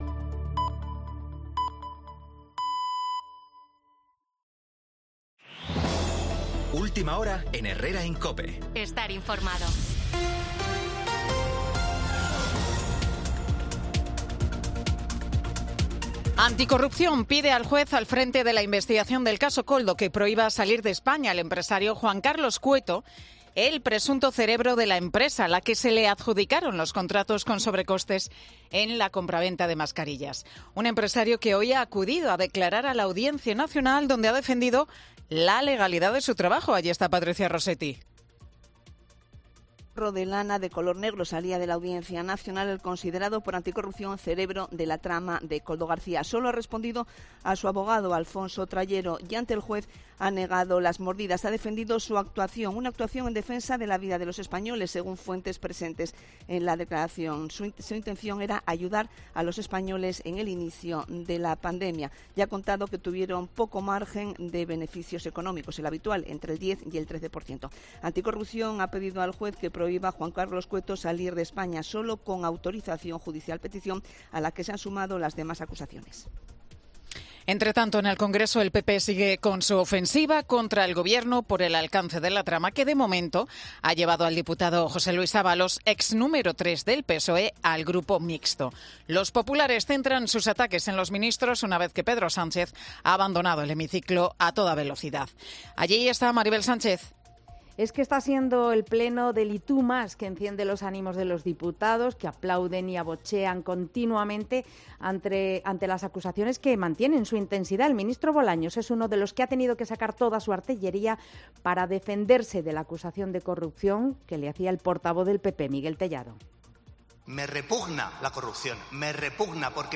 Boletín de Noticias de COPE del 28 de febrero del 2024 a las 11 horas